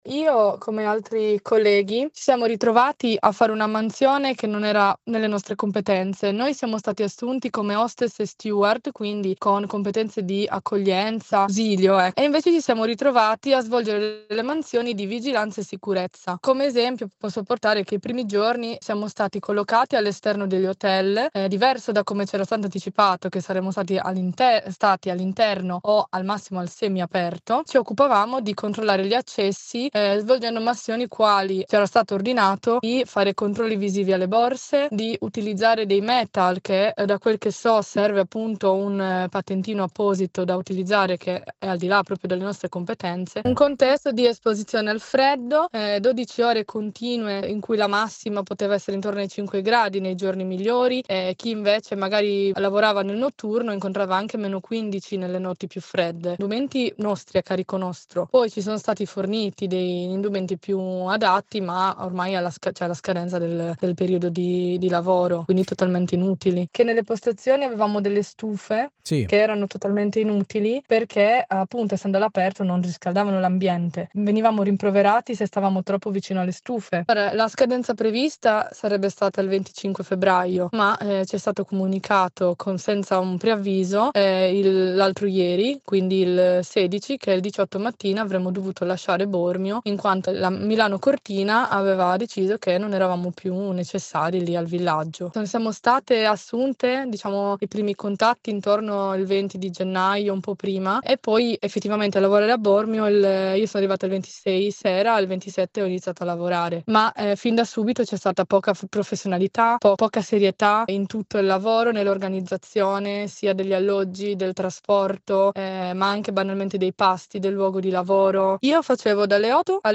alle 20.30. Lo racconta una delle lavoratrici coinvolte